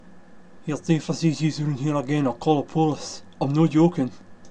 lilting cadence